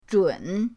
zhun3.mp3